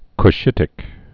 (k-shĭtĭk)